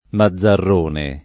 Mazzarrone [ ma zz arr 1 ne ]